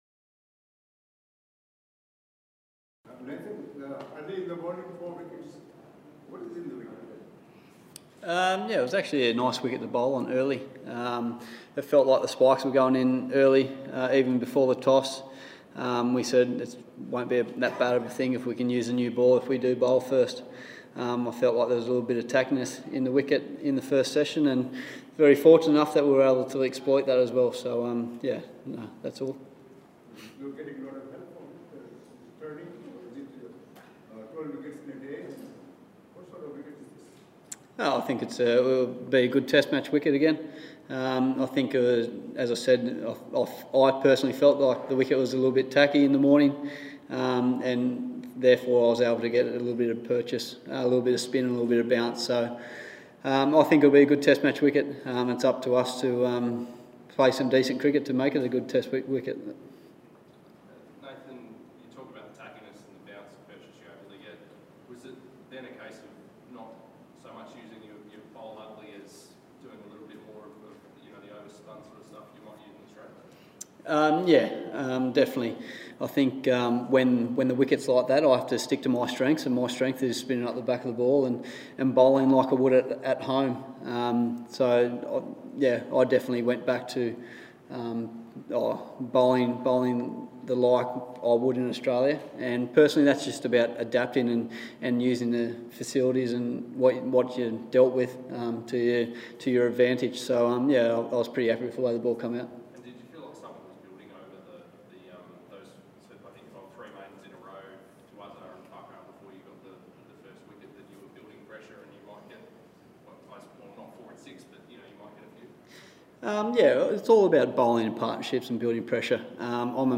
Nathan Lyon spoke to the media after passing Mitchell Johnson (313) to become Australia’s fourth highest Test wicket taker, with 314 scalps.